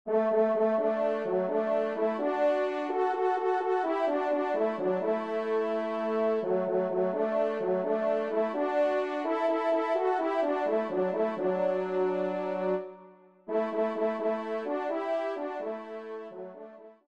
Trompe 2